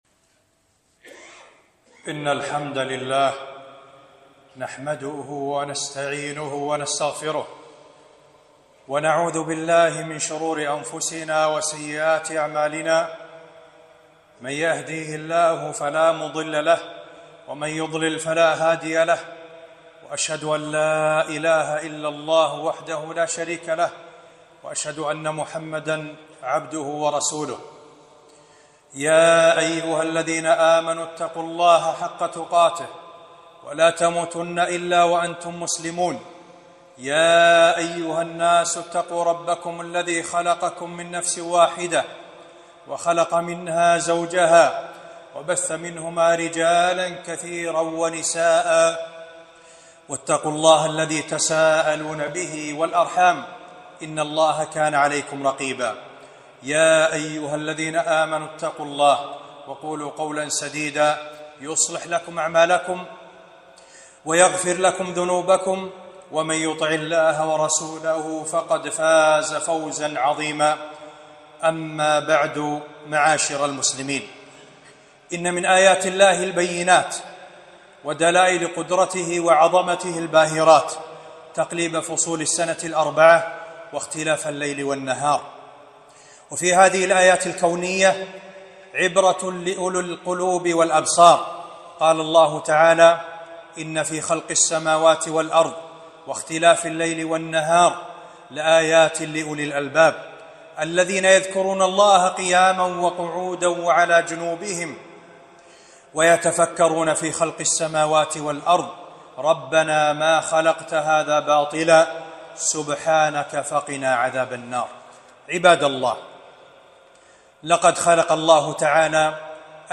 خطبة - حرارة الصيف وتذكرة الآخرة